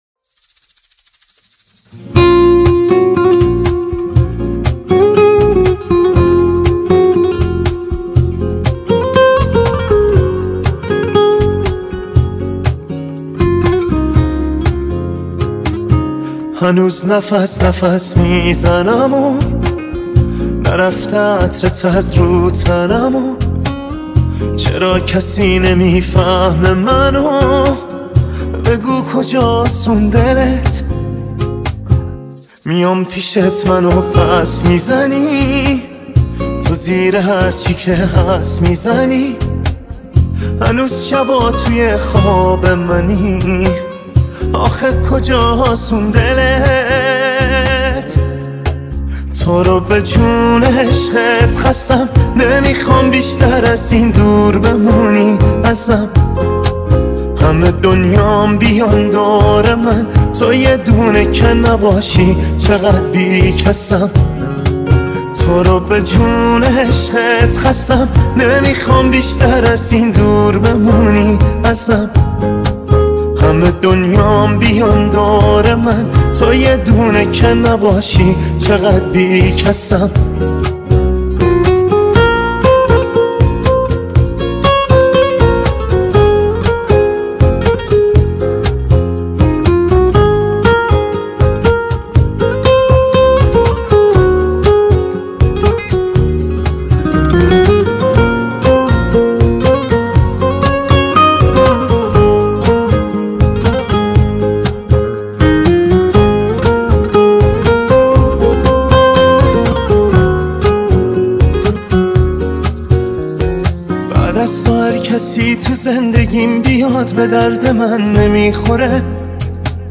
ورژن آکوستیک
غمگین